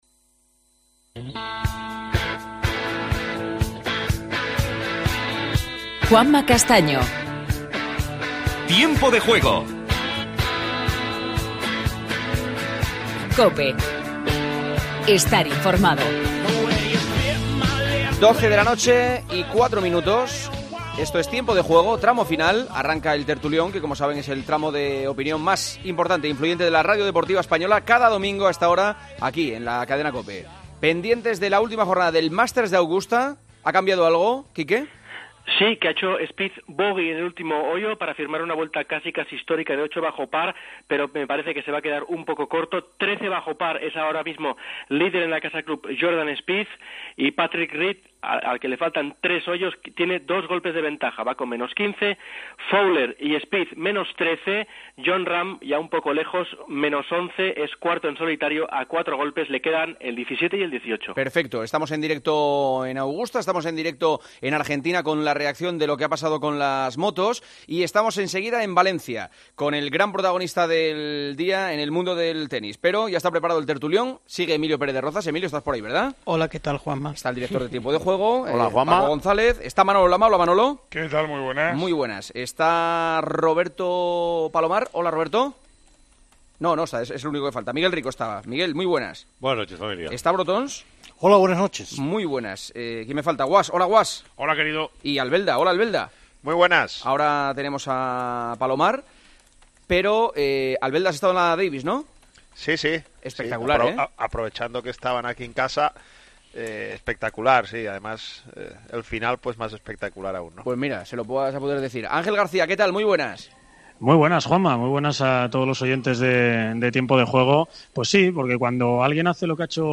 AUDIO: Entrevista a David Ferrer. Seguimiento de la última jornada del Masters de Augusta de golf.